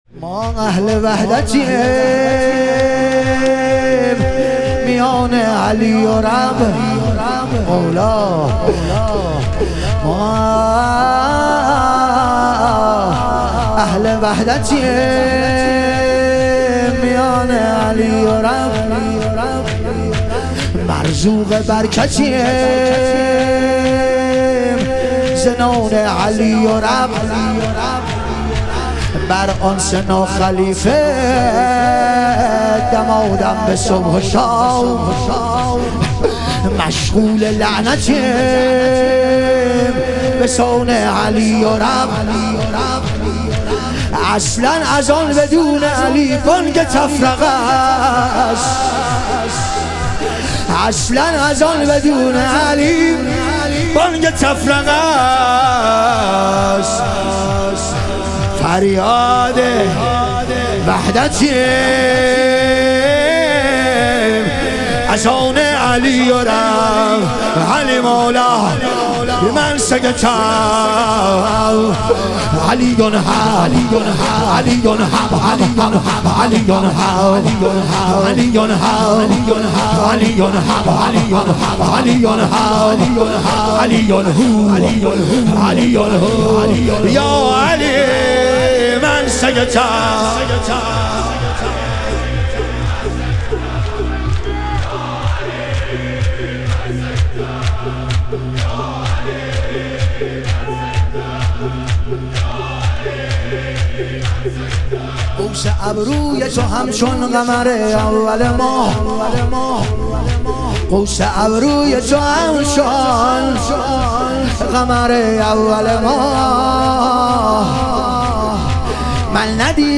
شهادت حضرت جعفرطیار علیه السلام - شور